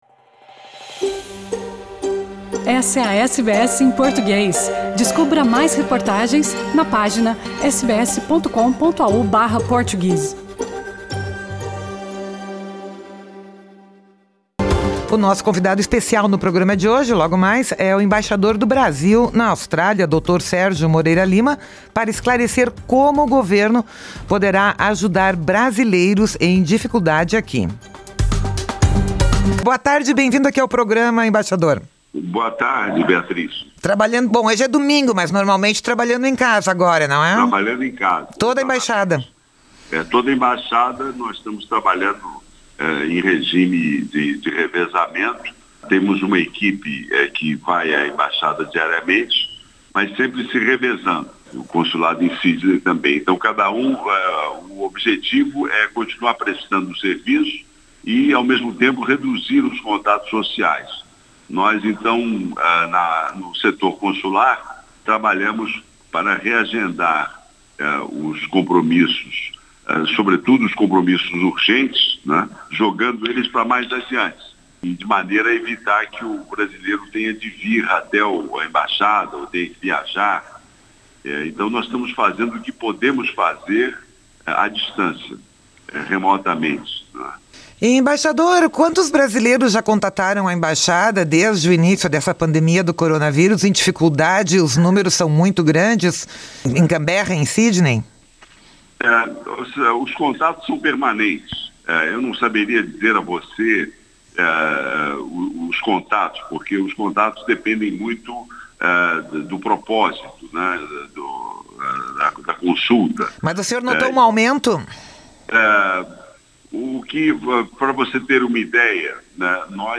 Neste podcast, o embaixador do Brasil na Austrália, Sérgio Moreira Lima, esclarece como o governo pode ajudar brasileiros em dificuldade aqui.
Acompanhe neste podcast a íntegra da entrevista.